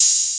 TM-88 Hats [Open Hat 4].wav